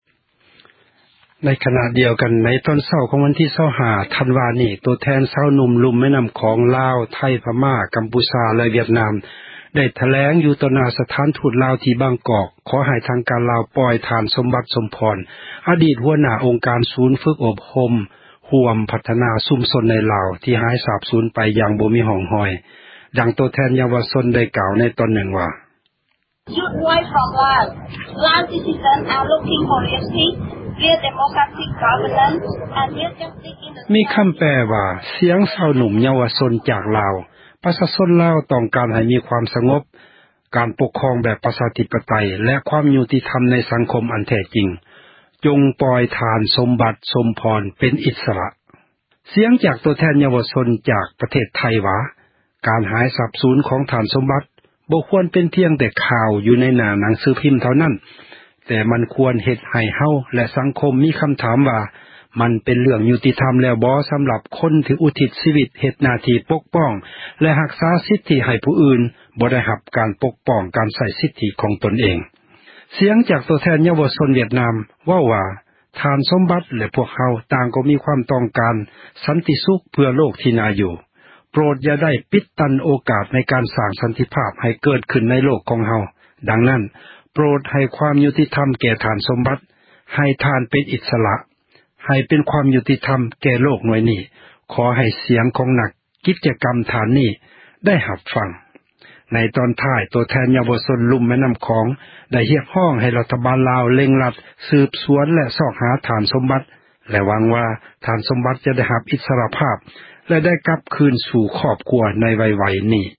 ໃນຕອນເຊົ້າ ຂອງ ວັນທີ່ 25 ທັນວາ 2012 ໂຕແທນ ຊາວໜຸ່ມ ລຸ່ມແມ່ນໍ້າຂອງ ລາວ ໄທ ພະມ້າ ກໍາພູຊາ ແລະ ວຽດນາມ ໄດ້ອອກຖແລງ ຢູ່ຕໍ່ໜ້າ ສະຖານທູດ ລາວ ທີ່ ບາງກອກ ຂໍໃຫ້ທາງການ ລາວ ປ່ອຍທ່ານ ສົມບັດ ສົມພອນ ອະດີດຫົວໜ້າ ອົງການ ສູນຝືກອົບຮົມ ຮ່ວມພັທນາ ຊຸມຊົນ ໃນລາວ ທີ່ ຫາຍສາບສູນ ຢ່າງໄຮ້ຮ່ອງຮອຍ.